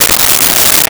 Unzip Metal Zipper 01
Unzip Metal Zipper 01.wav